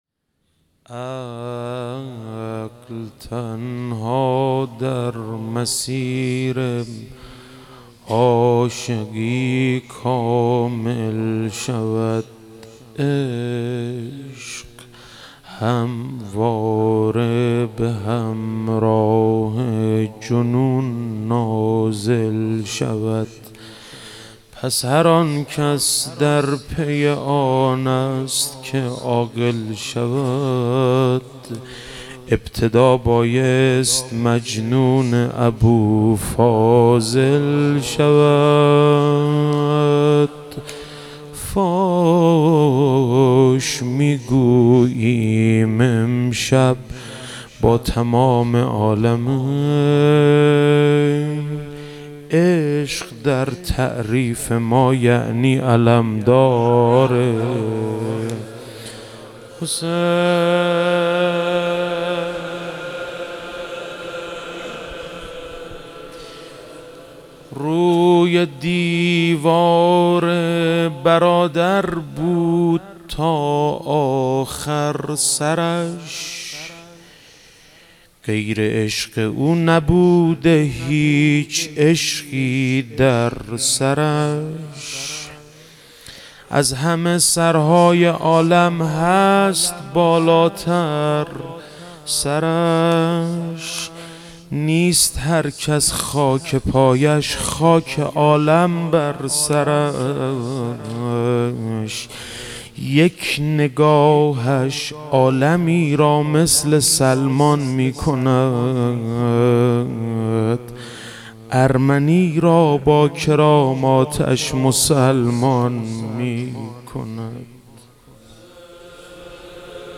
شب تاسوعا محرم 97 - روضه - عقل تنها در مسیر عاشقی
محرم 97